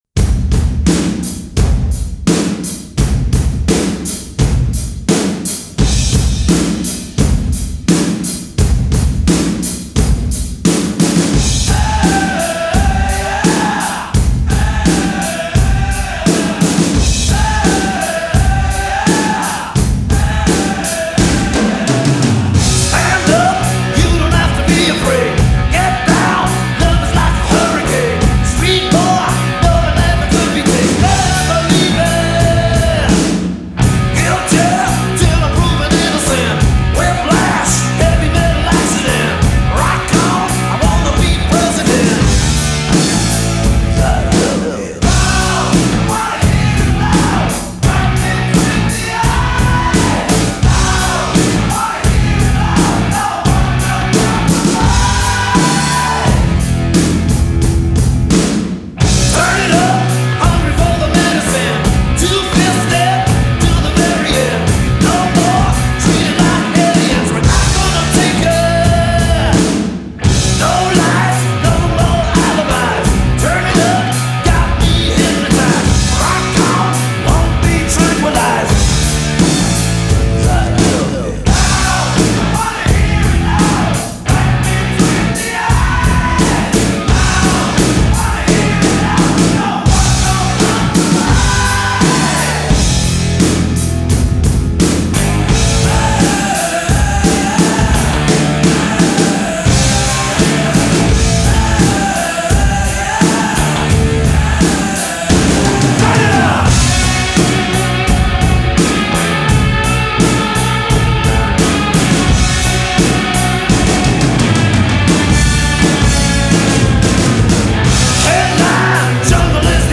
BPM80-92
Audio QualityPerfect (High Quality)